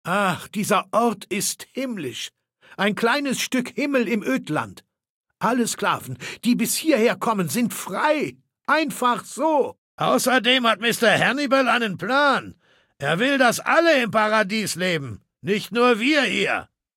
Datei:Maleold01 ms06 ms06templeunion 0005a213.ogg
Fallout 3: Audiodialoge